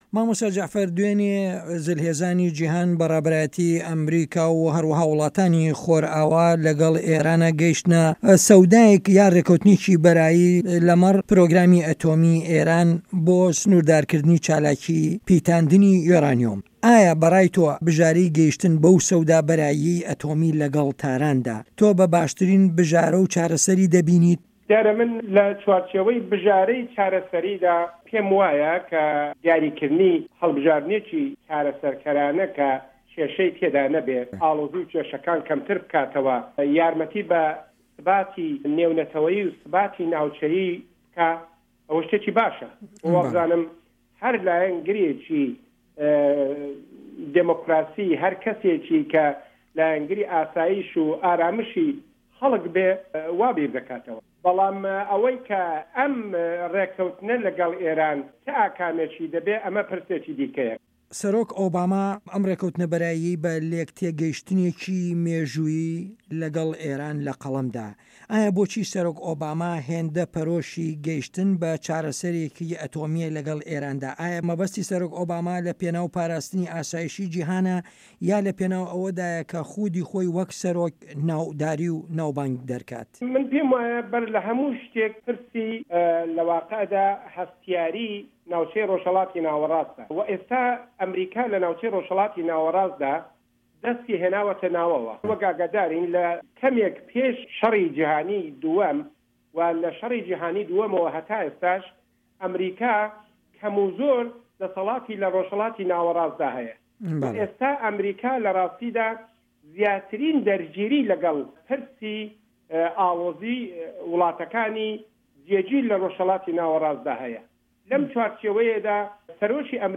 توێژ